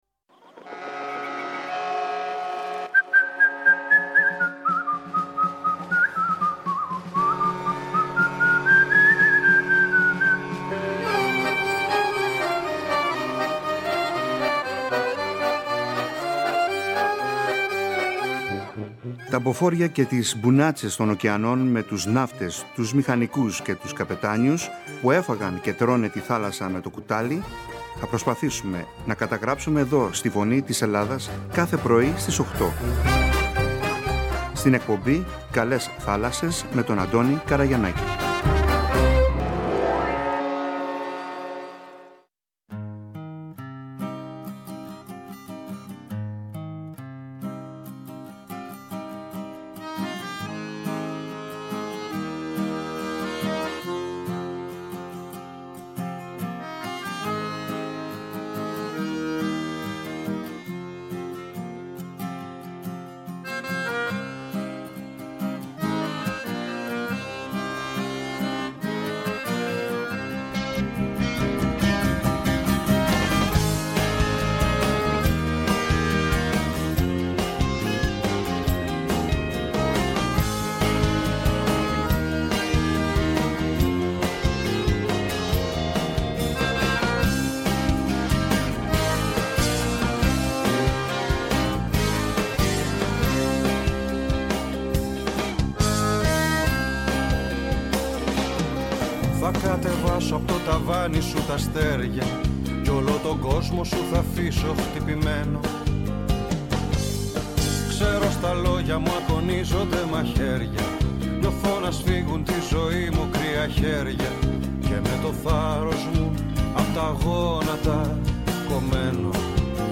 Είναι πιο πολύ ανθρωποκεντρική εκπομπή για τον ναυτικό, με τα θέματα του, τη ναυτιλία, τραγούδια, ιστορίες, συναισθήματα, σκέψεις, και άλλα πολλά όπως π.χ η γυναίκα εργαζόμενη στη ναυτιλία, η γυναίκα ναυτικού, είδη καραβιών, ιστορίες ναυτικών οικογενειών, ιστορίες ναυτικών, επικοινωνία μέσω του ραδιοφώνου, ναυτικές ορολογίες, τραγούδια, ποίηση, πεζογραφία για τη θάλασσα, εξαρτήματα του πλοίου, ήδη καραβιών ναυτικά επαγγέλματα κλπ κλπ Ήδη έχουν ανταποκριθεί αρκετοί, παλιοί και εν ενεργεία καπετάνιοι και μίλησαν με μεγάλη χαρά και συγκίνηση για την ζωή – καριέρα τους στην θάλασσα και τι σημαίνει για αυτούς.